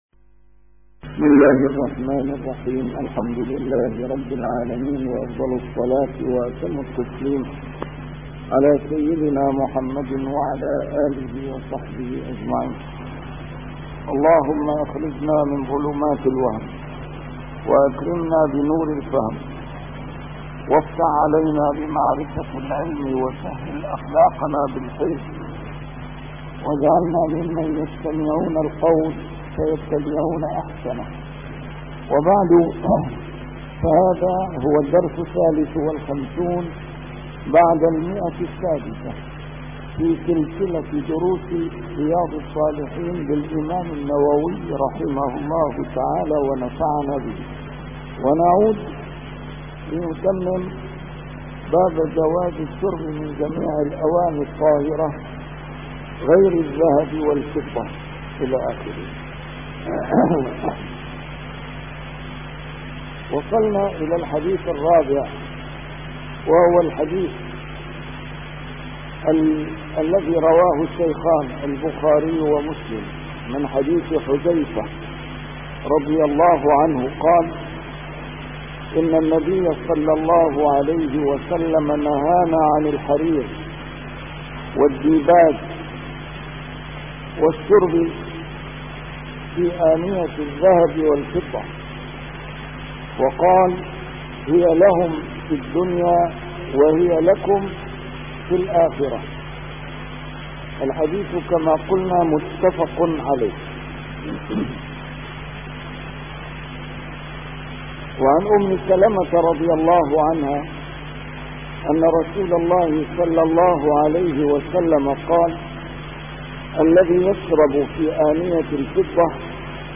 A MARTYR SCHOLAR: IMAM MUHAMMAD SAEED RAMADAN AL-BOUTI - الدروس العلمية - شرح كتاب رياض الصالحين - 653- شرح رياض الصالحين: الشرب من الأواني الطاهرة